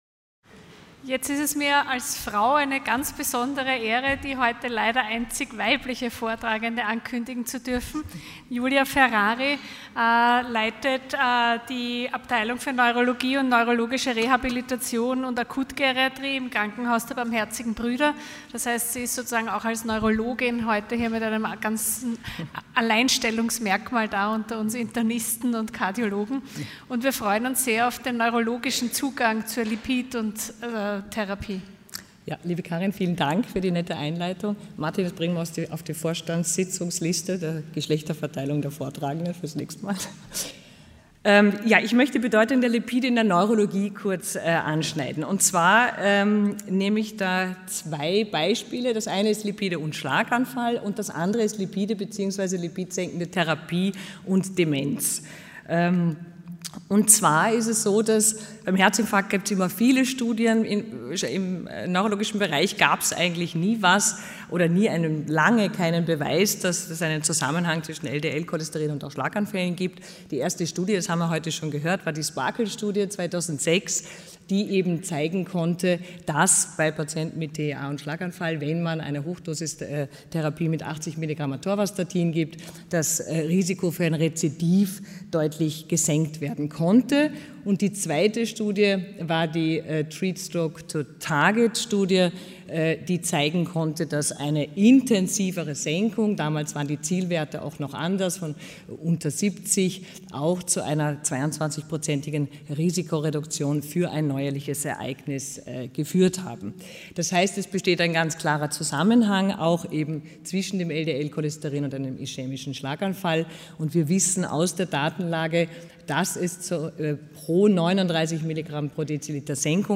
Sie haben den Vortrag noch nicht angesehen oder den Test negativ beendet.
Hybridveranstaltung | Lange Nacht der Lipide in Kooperation mit der Cholesterinallianz